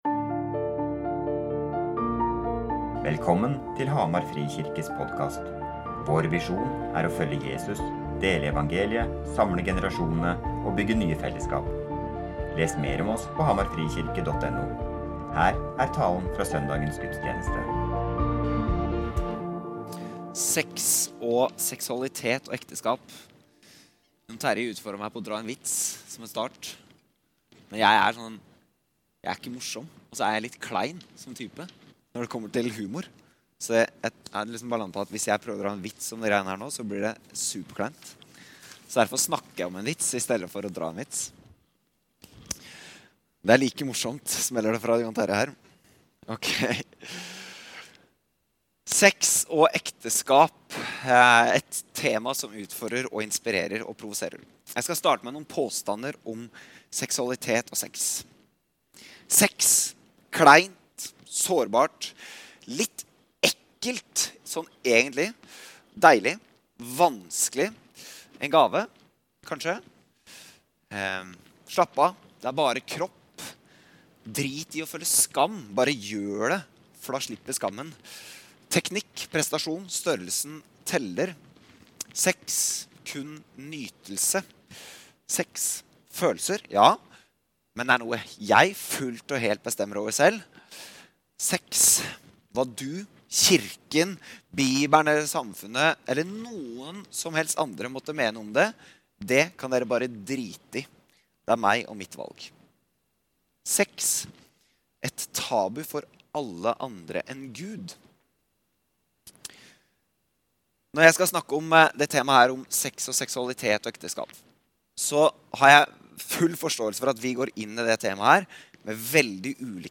Talen er andre del av taleserien vår «Snakk om det!»
Gudstjenesten